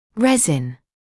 [‘rezɪn][‘рэзин]смола; пластмасса